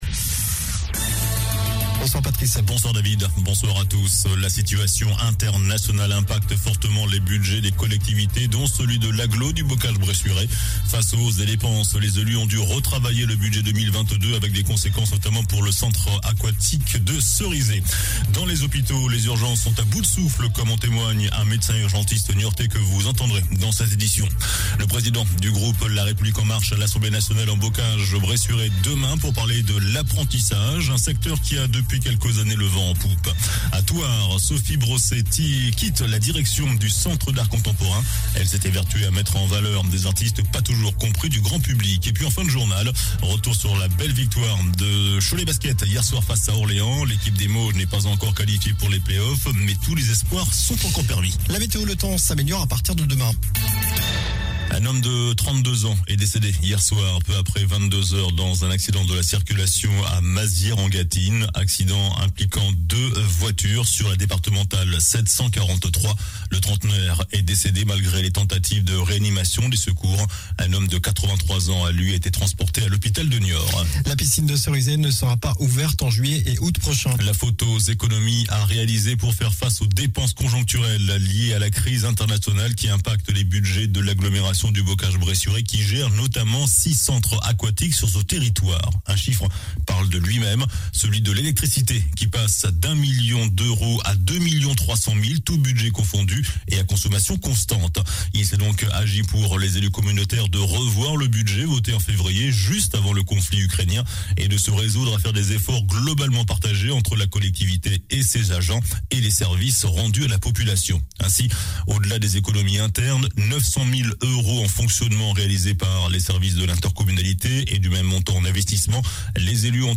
JOURNAL DU MERCREDI 13 AVRIL ( SOIR )